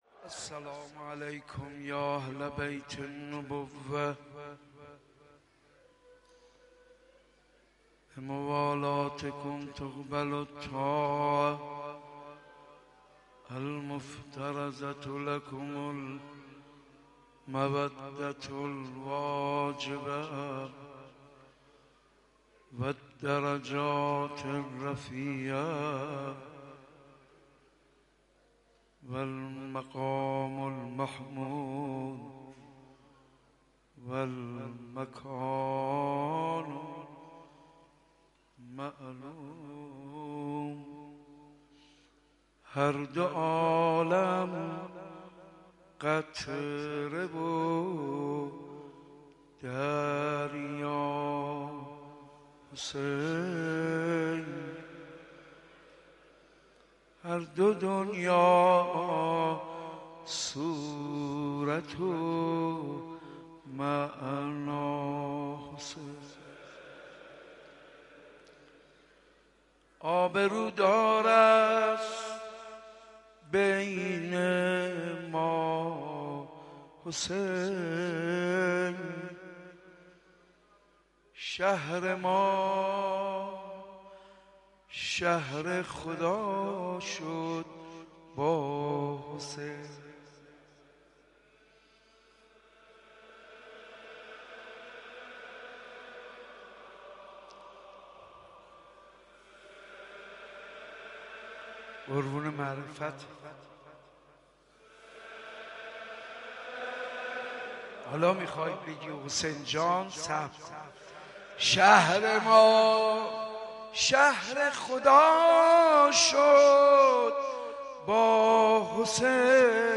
اولین شب عزاداری ایام محرم ۱۴۳۸ در حسینیه امام خمینی